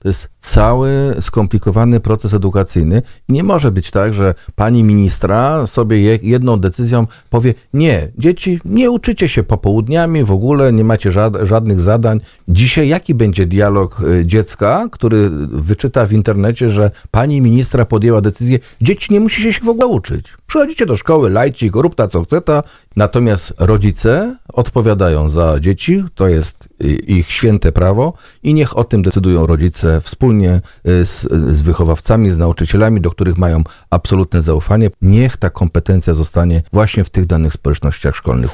To jest dziecinada edukacyjna i wprowadzenie polityki 'róbta co chceta’ – mówił na antenie RDN Małopolska poseł PiS Wiesław Krajewski, komentując zapowiadane plany zniesienia zadań domowych w szkołach podstawowych.
Od kwietnia z zadań domowych mają być zwolnione dzieci z klas 1-3. Zdaniem posła Wiesława Krajewskiego, który był gościem rozmowy Słowo za Słowo, takie działanie przysporzy dzieciom i młodzieży więcej szkód niż pożytku.